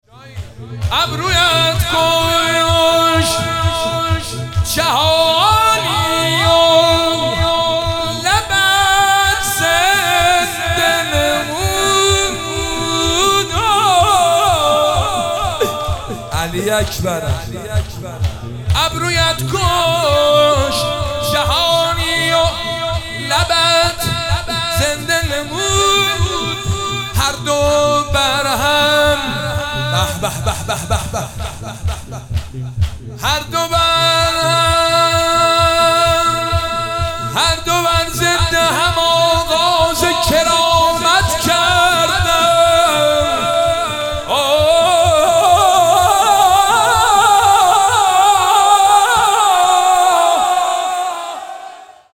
شعر خوانی